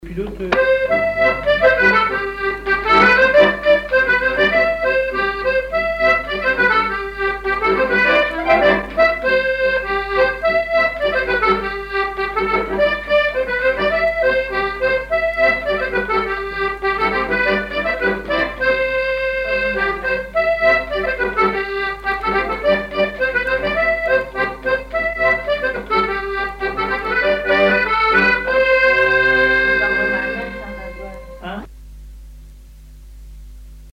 danse : scottich trois pas
accordéon diatonique
Pièce musicale inédite